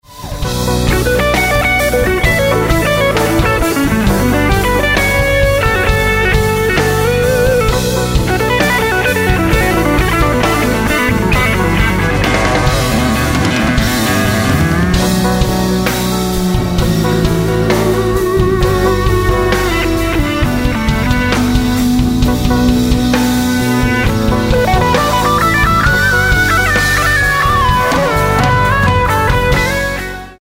acoustic & electric guitars
drums
keyboards, lyricon, tenor saxophone
electric bass, vocals